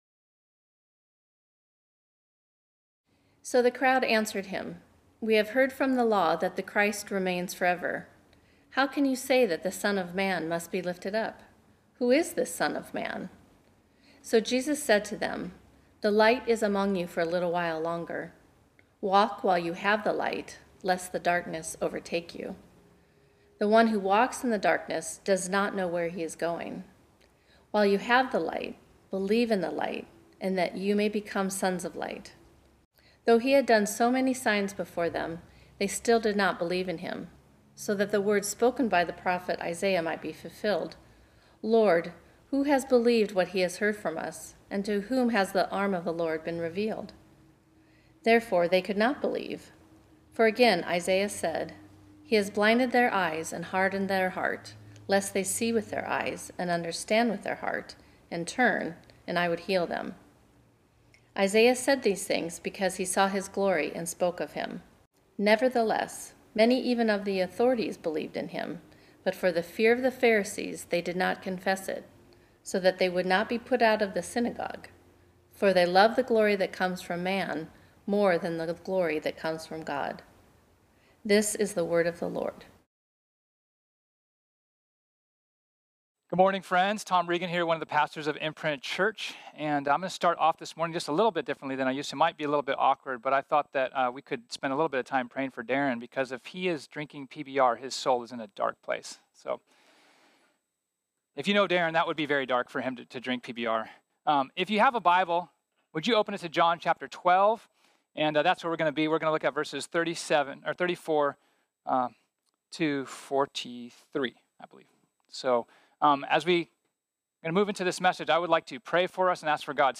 This sermon was originally preached on Sunday, April 19, 2020.